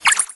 蝙蝠Bats是常见的空军卡牌， 音效都类似于蝙蝠叫声。
攻击音效
CR_bat_atk_01.mp3